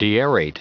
Prononciation du mot deaerate en anglais (fichier audio)